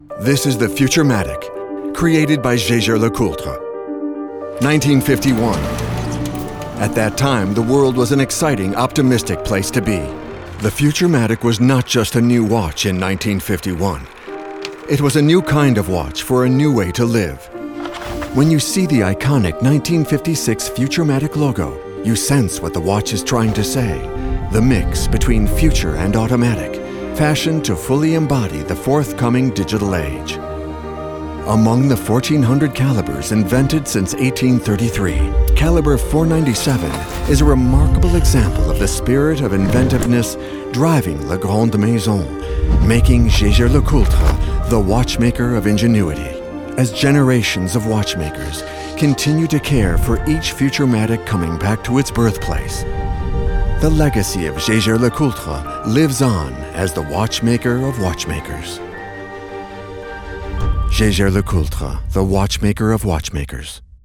Naturelle, Polyvalente, Chaude, Mature, Corporative
Corporate